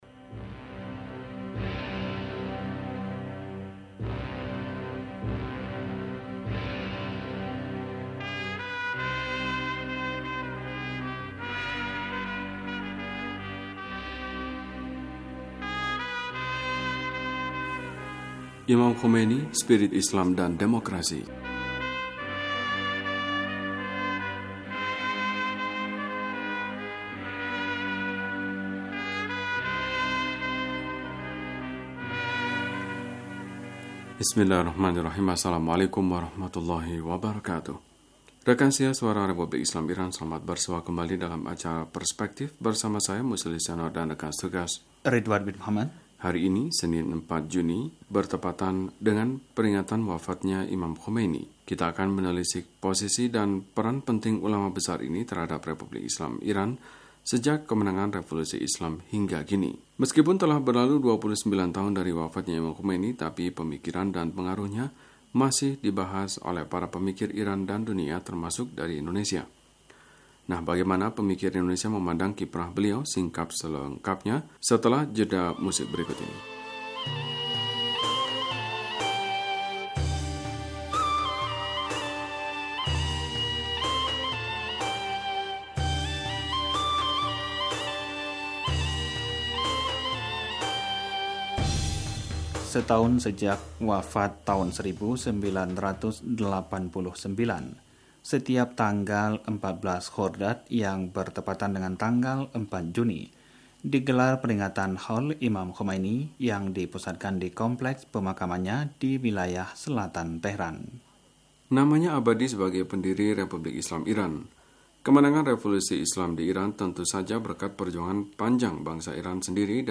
Wawancara: Imam Khomeini, Spirit Islam dan Demokrasi